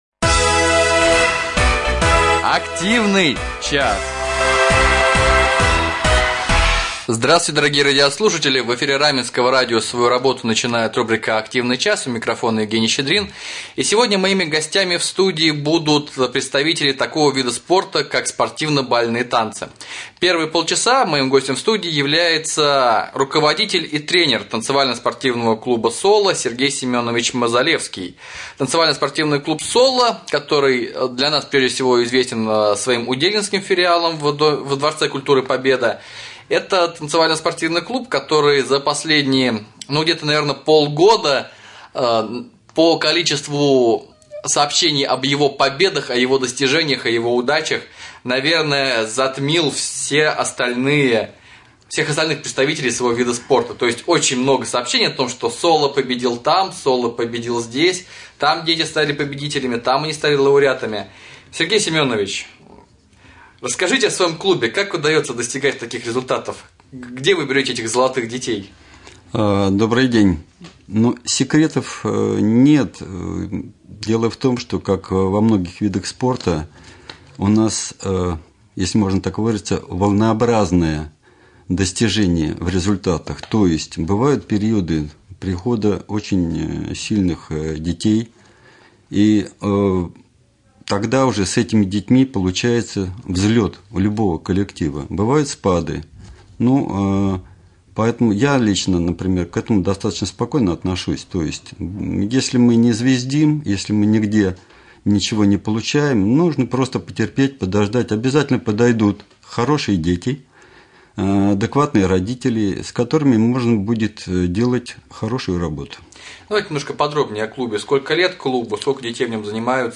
В гостях у Раменского радио побывали представители такого эстетически прекрасного вида спорта как Спортивно-Бальные танцы из танцевально-спортивного клуба «Соло» и танцевально-спортивного клуба «Вега-Степ».